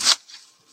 PixelPerfectionCE/assets/minecraft/sounds/mob/sheep/shear.ogg at mc116
shear.ogg